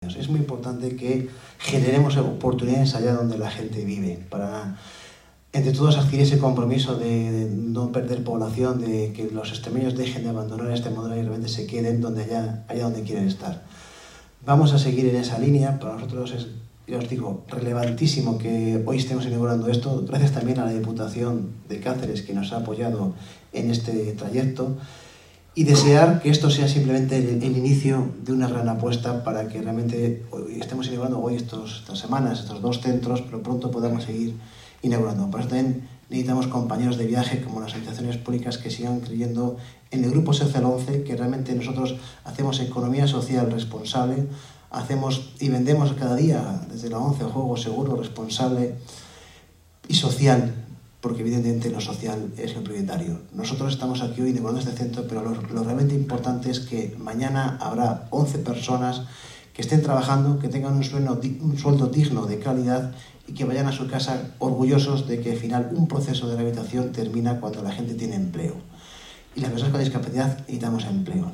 en acto de inauguración del centro de Jaraíz.